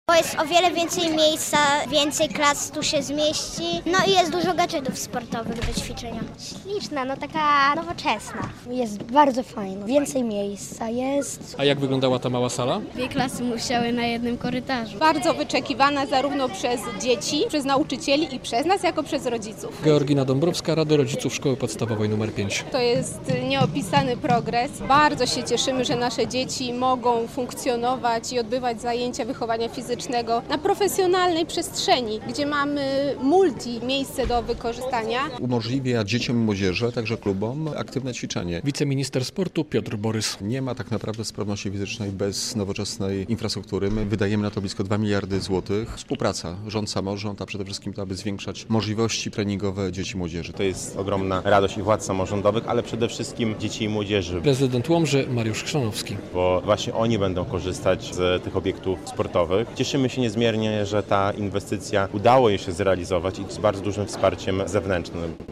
SP 5 w Łomży ma nową halę sportową - relacja